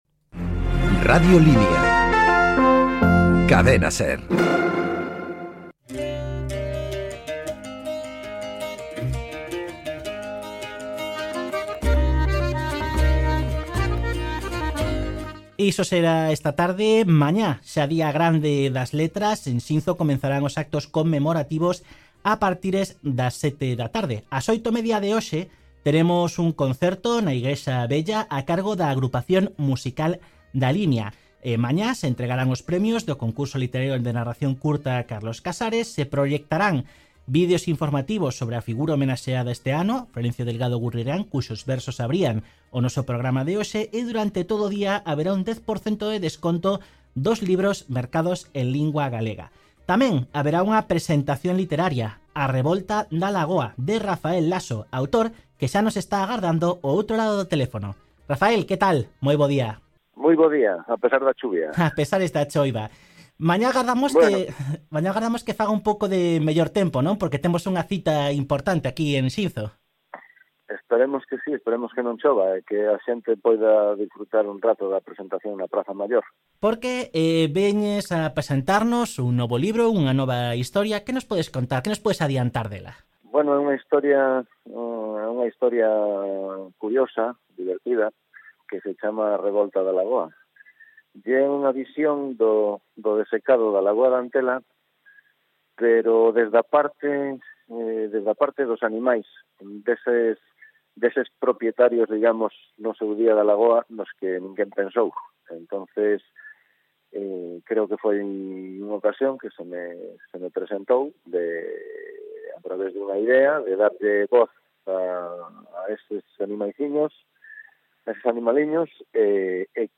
A revolta da lagoa - Entrevista